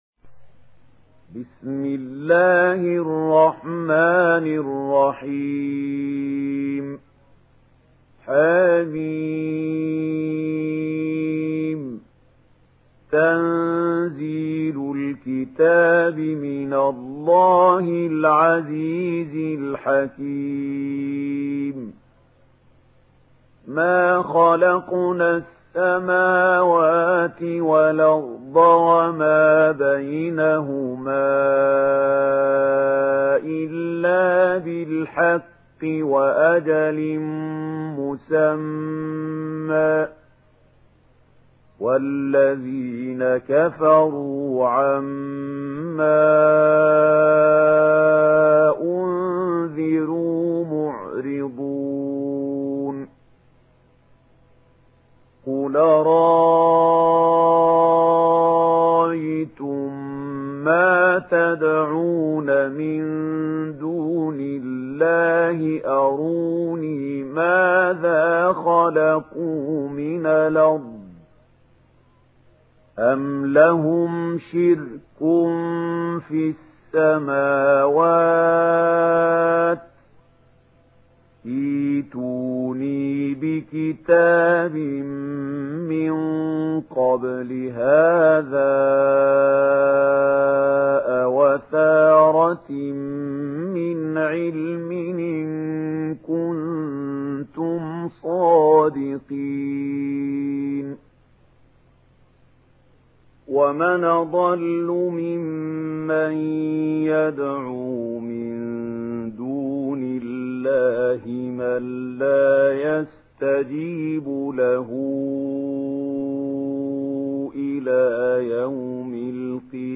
سورة الأحقاف MP3 بصوت محمود خليل الحصري برواية ورش
مرتل ورش عن نافع